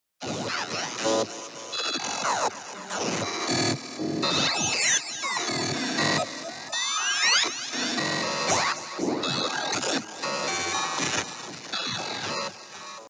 Системная ошибка